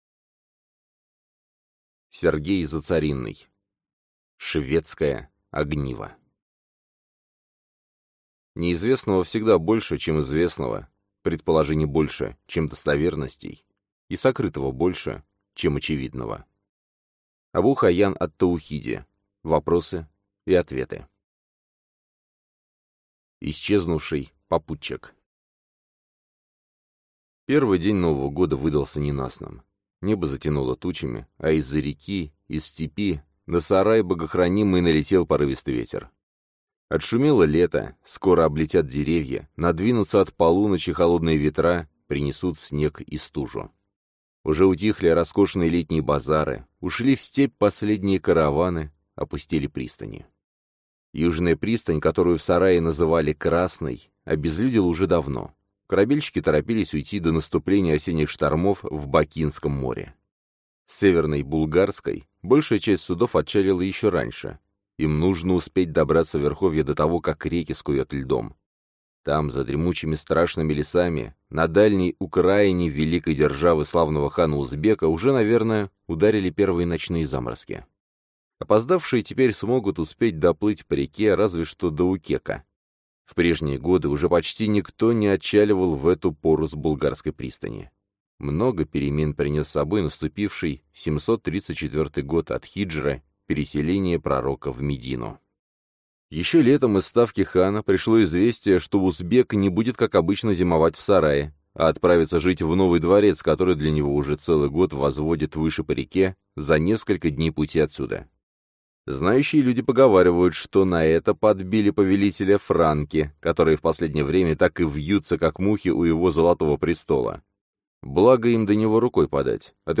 Аудиокнига Шведское огниво | Библиотека аудиокниг